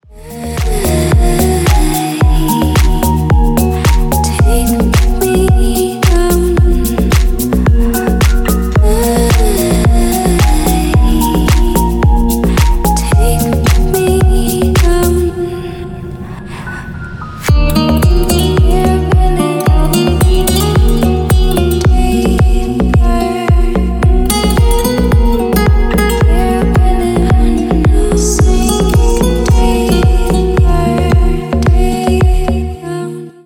Танцевальные
клубные # спокойные